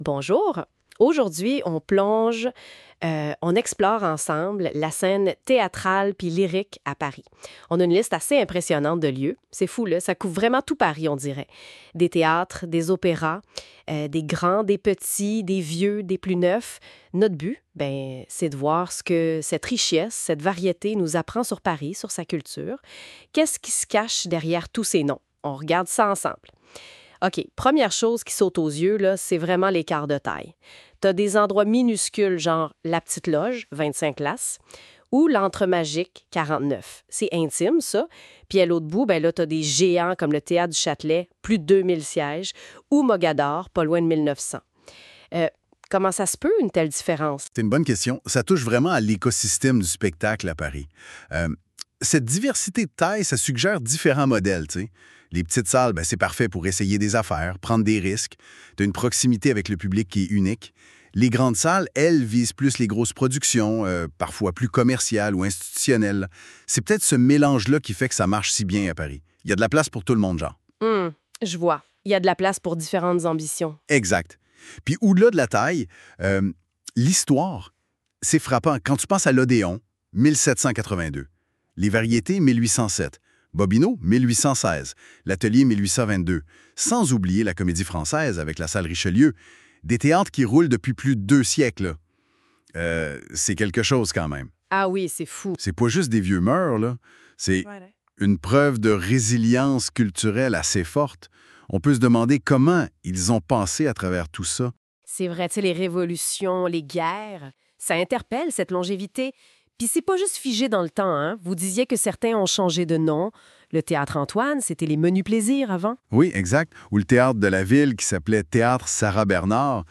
Québécois